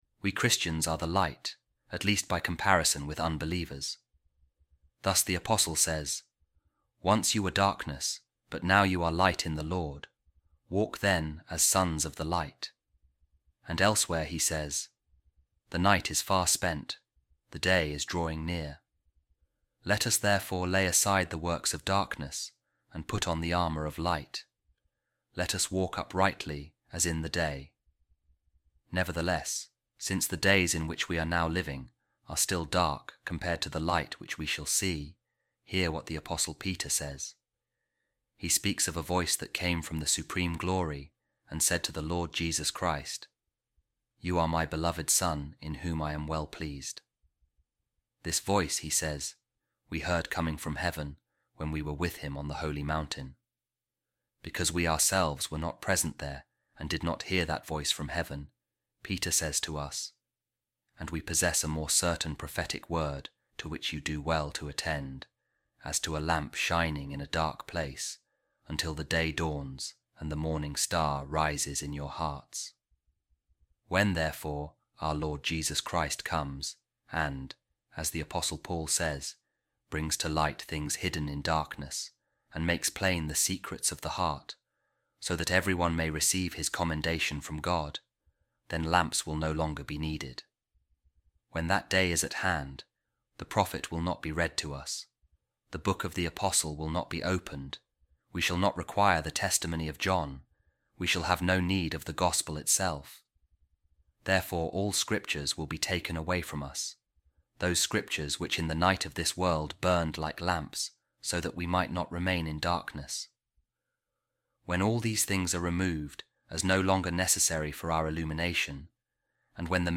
A Reading From The Homilies Of Saint Augustine On Saint John’s Gospel | You Will Come To The Fountain, And You Will See The Light Itself